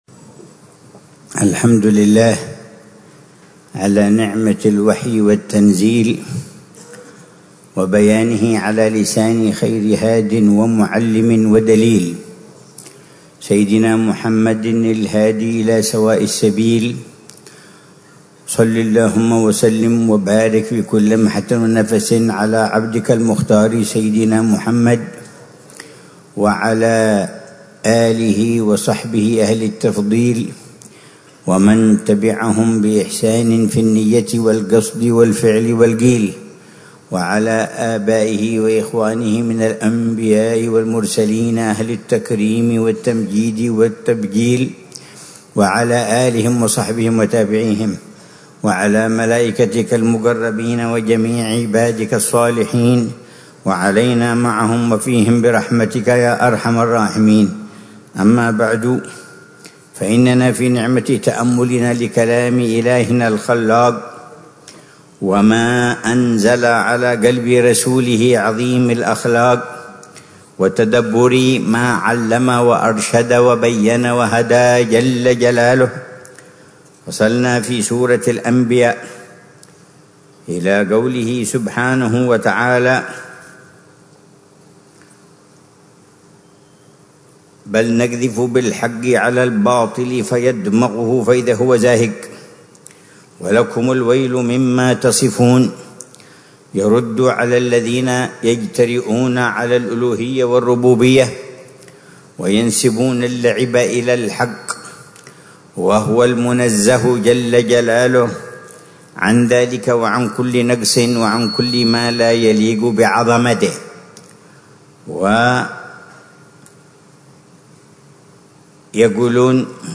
تفسير فضيلة العلامة الحبيب عمر بن محمد بن حفيظ للآيات الكريمة من سورة الأنبياء